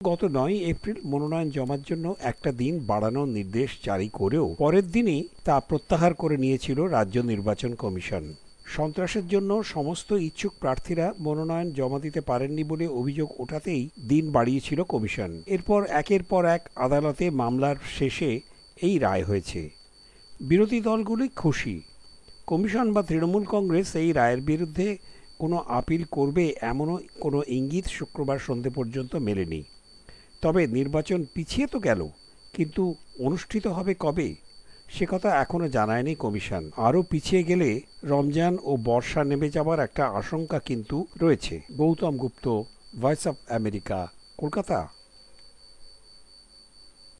রিপোর্ট